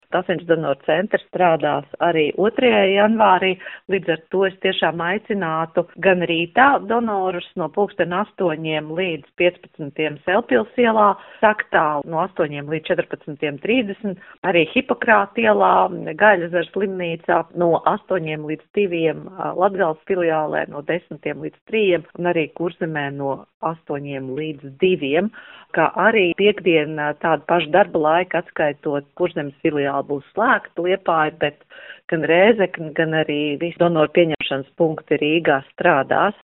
intervijā Skonto mediju grupai